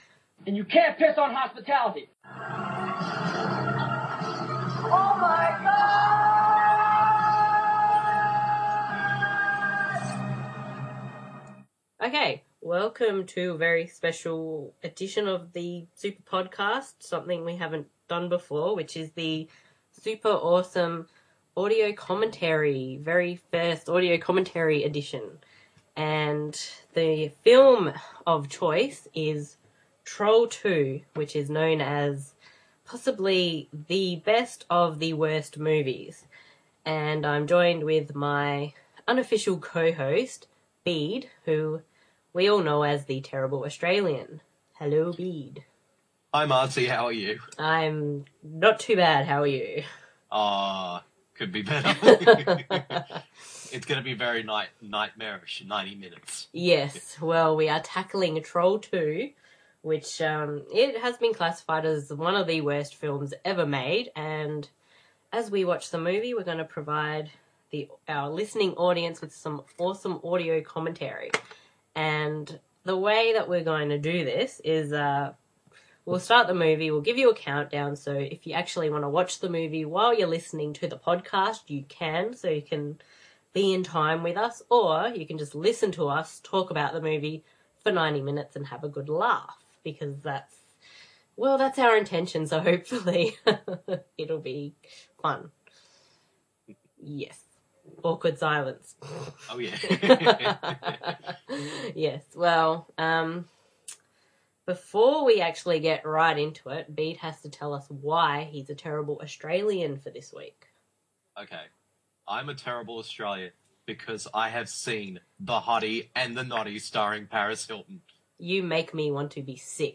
The idea behind this is, you can watch the film and listen to the commentary at the same time, or you can just listen to the commentary and perhaps get convinced to check out the film.